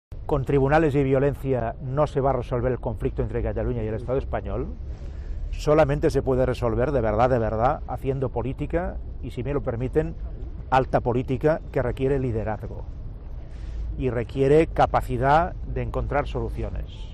Mas ha hecho estas declaraciones a los periodistas a la entrada de la Audiencia Nacional, donde ha acudido junto con un buen número de dirigentes independentistas y miembros de Unidos Podemos para apoyar a la veintena de imputados por rebelión, sedición y malversación por la declaración de independencia.